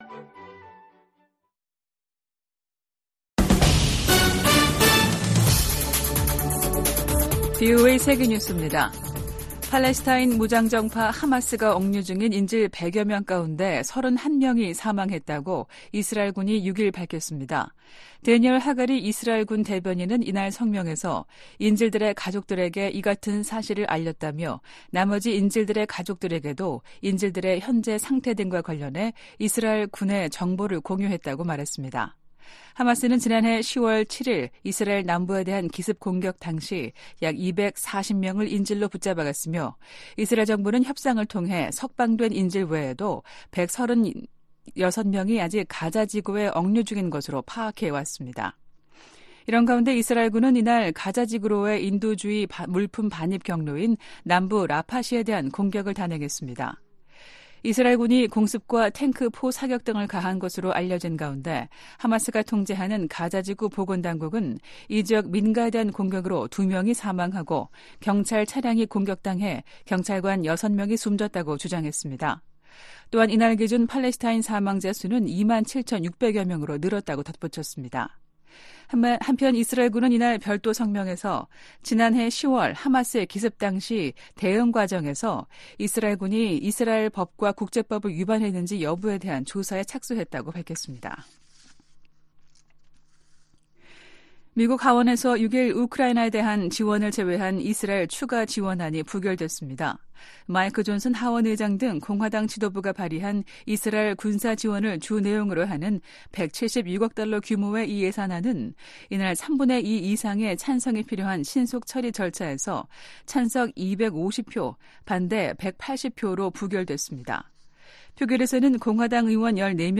VOA 한국어 아침 뉴스 프로그램 '워싱턴 뉴스 광장' 2024년 2월 8일 방송입니다. 미국이 유엔 안보리에서 러시아의 북한제 무기 사용을 강하게 비난하고, 양국에 책임 묻기를 촉구했습니다. 한국 신임 외교부 장관과 중국 외교부장이 첫 통화를 하고 현안을 논의했습니다.